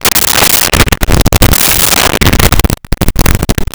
Door Heavy Open Close 01
Door Heavy Open Close 01.wav